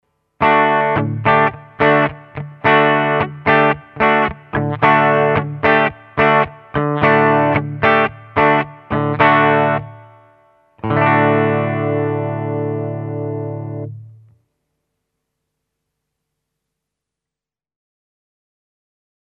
The P-90
A wider coil isn’t able to pick up high frequencies (treble) as well as a narrow one, which is why a P-90 generally has a more middly tone than a Fender-singlecoil:
LP Junior clean
lp-junior-clean.mp3